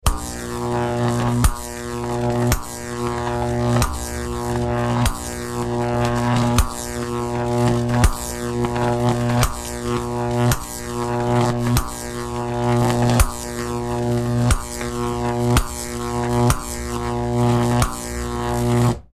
Jacob's Ladder 2; Large, Low Frequency, Slow, Repetitive Electrical Build Up And Discharge; Close Perspective. Science Lab, Spark, Arc.